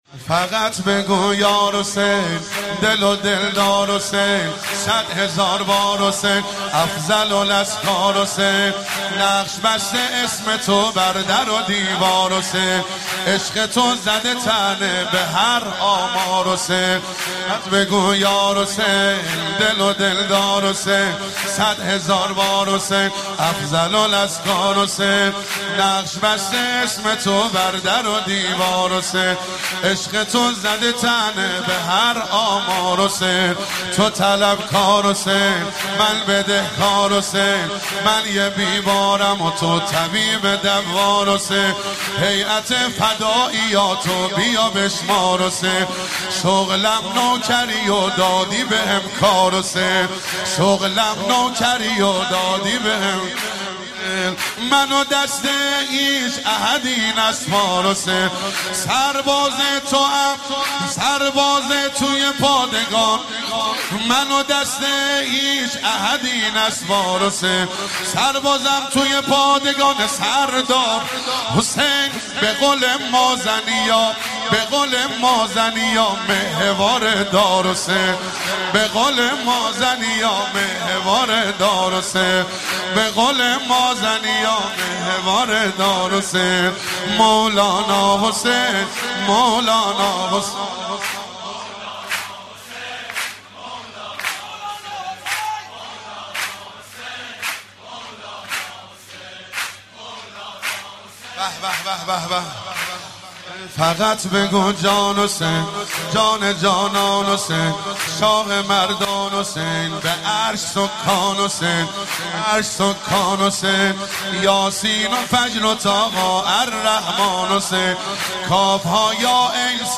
سرود ولادت
نوحه مداحی